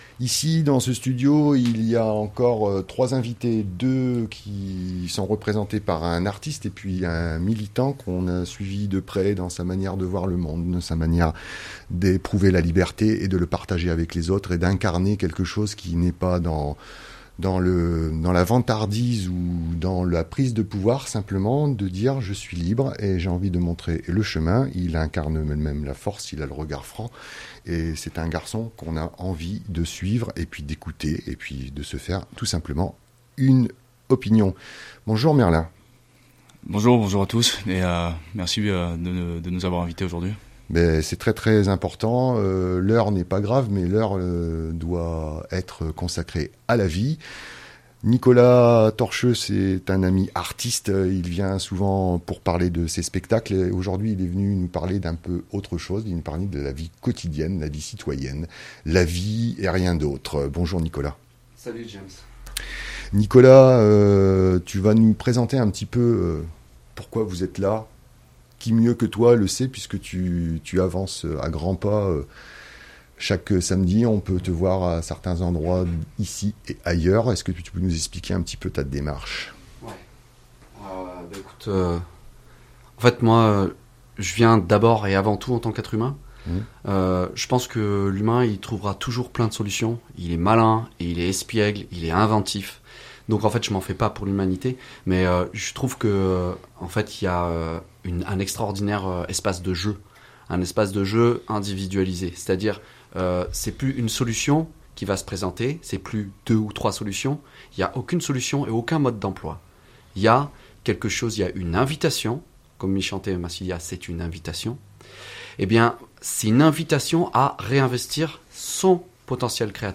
Au Café des Libertés on discute, on rit, on chante, on danse, on critique, on s'exaspère, on pique-nique, on refait le monde comme dans cette entrevue